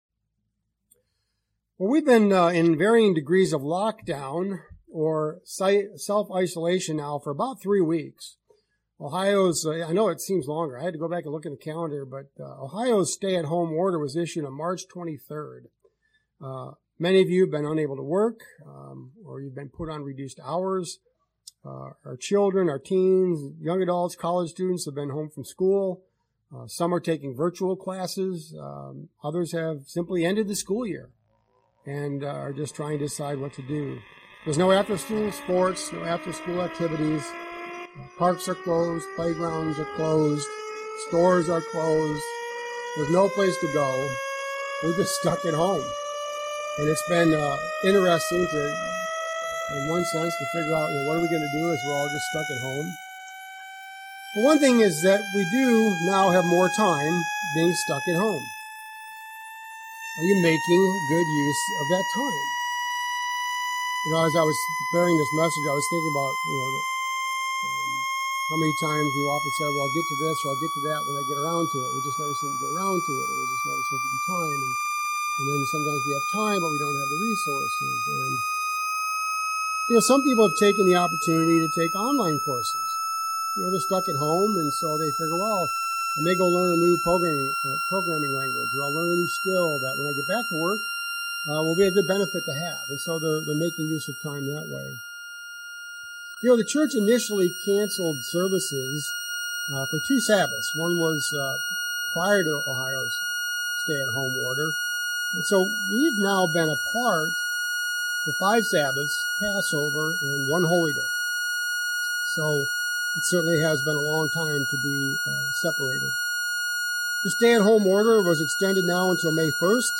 ***Please note: A faulty microphone cord caused audio problems in the first 4 minutes of this recording.